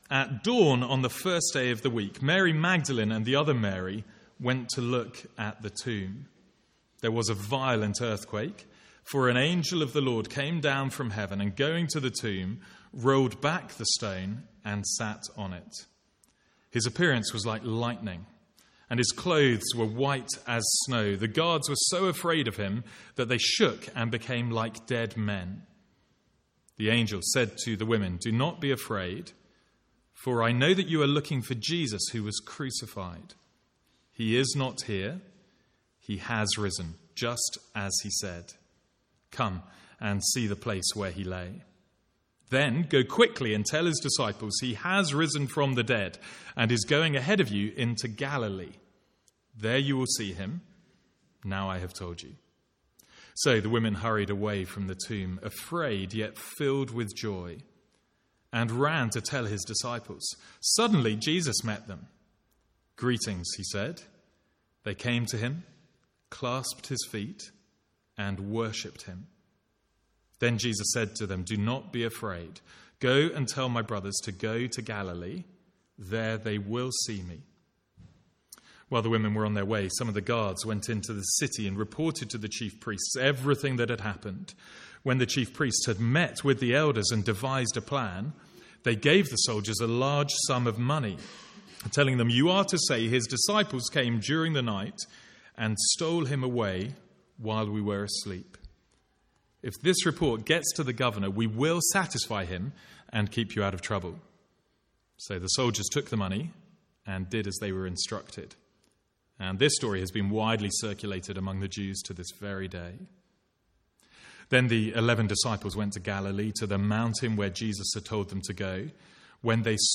From the Sunday morning series in Matthew.